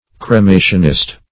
Cremationist \Cre*ma"tion*ist\, n. One who advocates the practice of cremation.
cremationist.mp3